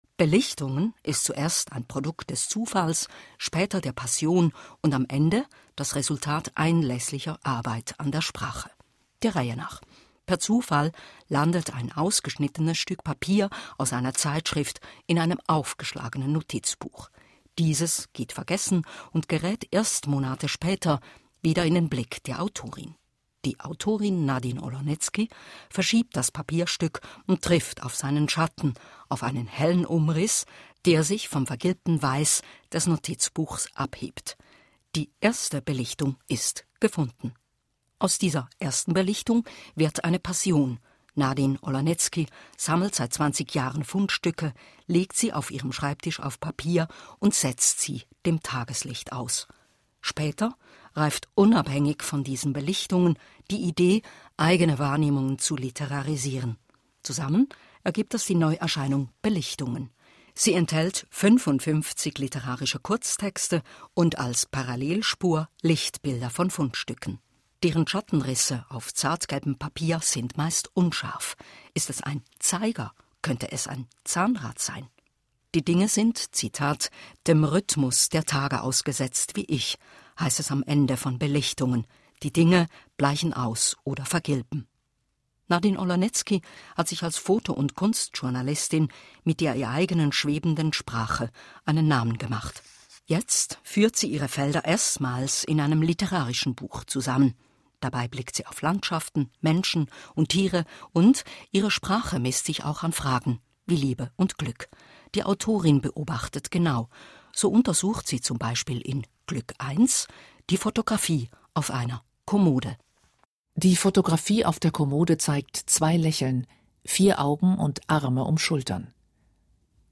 Besprechung auf Radio SRF II